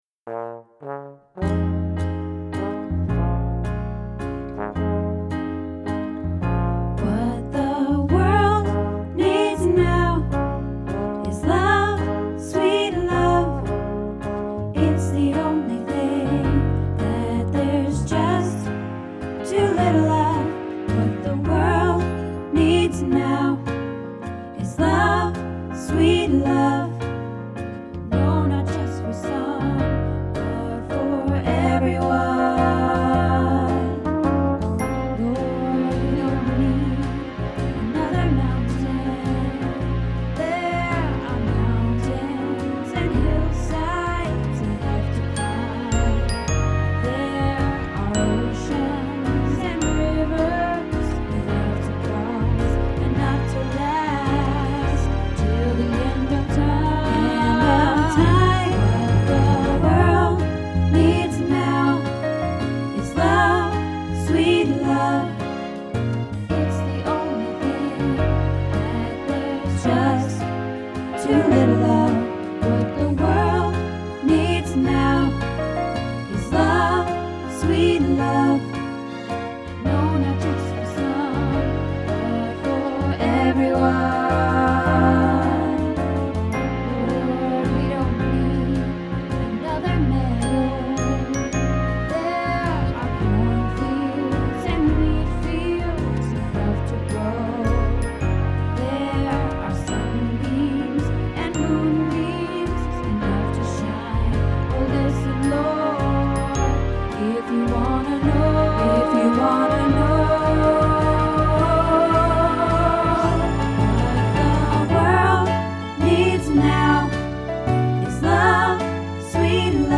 What the World Needs Now - Bass